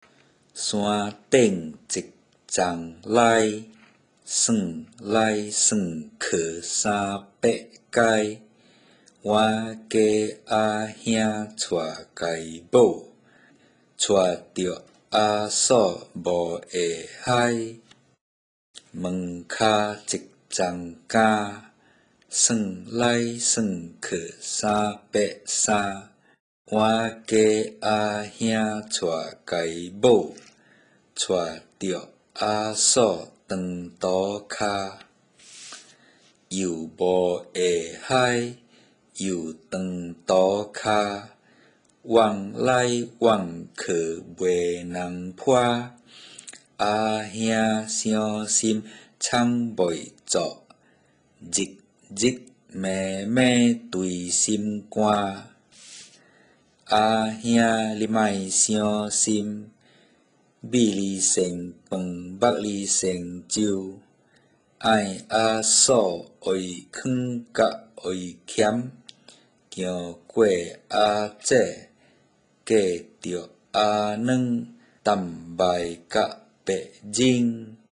Click "Read" against each Teochew Nursery Rhyme to listen to it in normal Teochew.
HillTop_Read.mp3